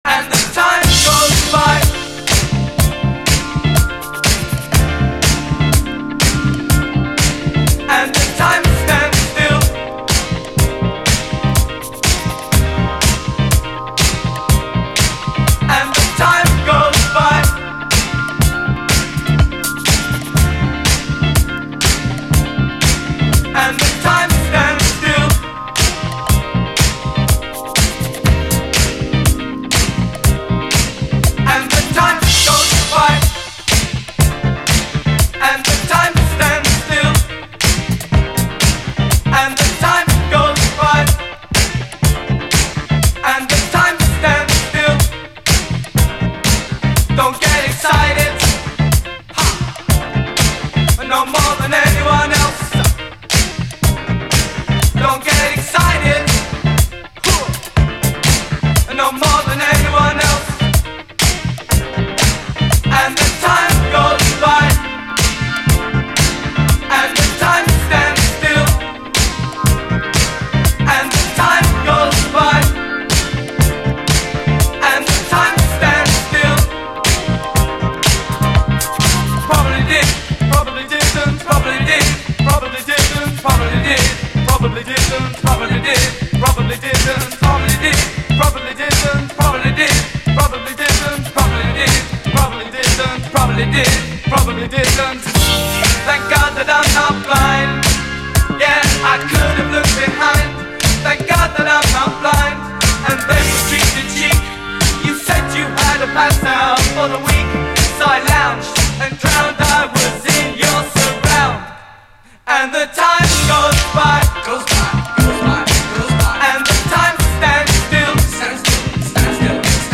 奇跡的なメロウ・トロピカル・ステッパー
UKラヴァーズの感触をダンサブルなメロウ・ディスコに落とし込んだ最高のインスト・ディスコ・レゲエ
しなやかなメロディーに魅了されるラヴァーズ・チューン
リラクシンなメロウ・グルーヴ
レゲエの枠に収まらない洒落たサウンドが全編に漂う素晴らしいアルバム